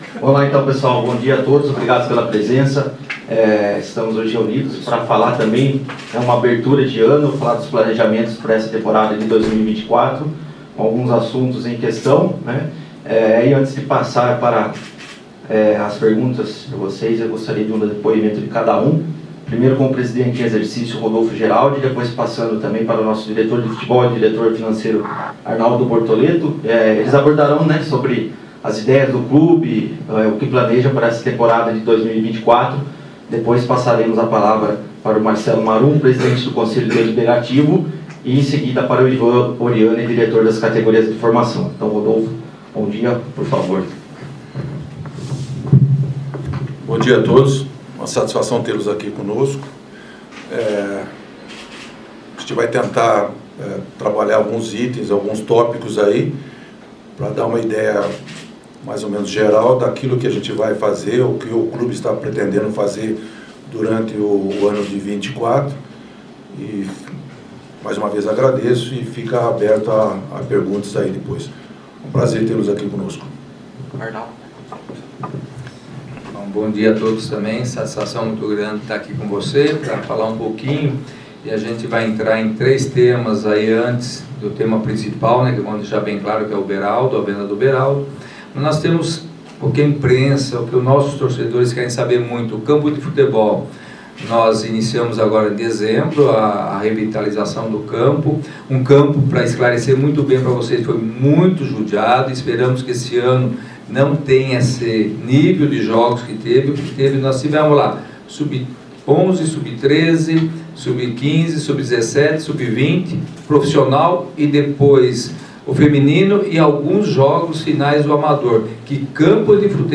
Entrevista-coletiva-diretoria-XV-de-Piracicaba-2024.mp3